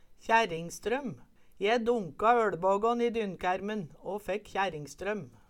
kjærringstrøm - Numedalsmål (en-US)